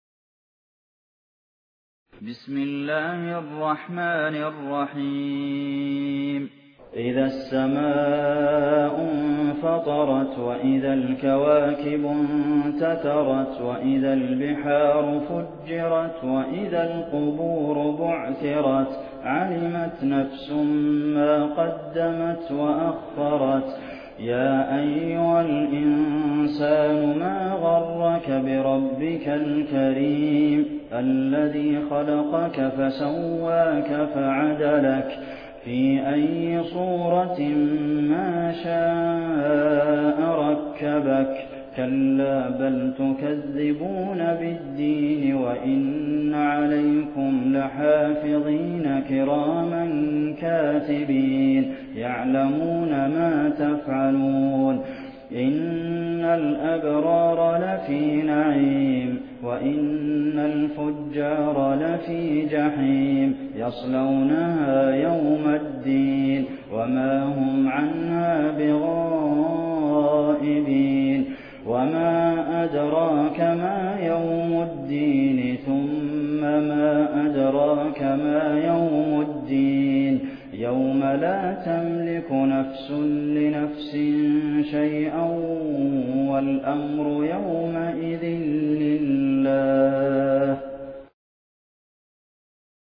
1429 صلاة التراويح عام 1429هـ من المسجد النبوى